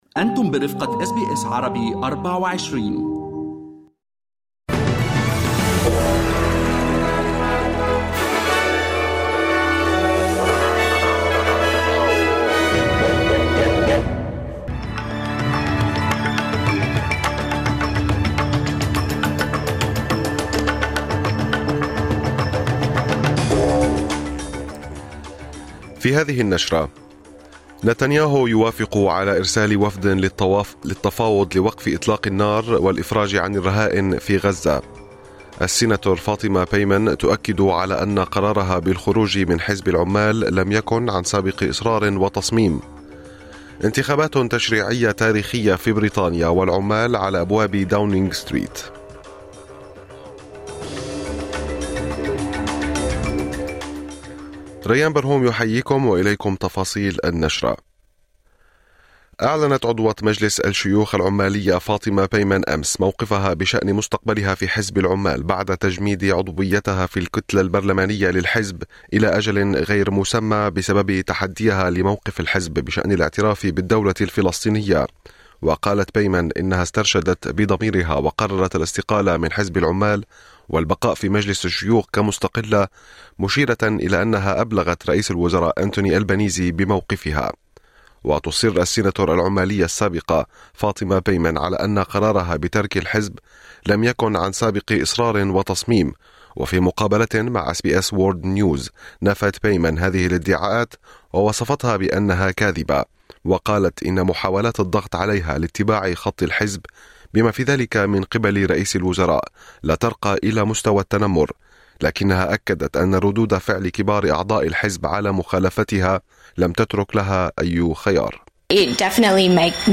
نشرة أخبار الصباح 05/07/2024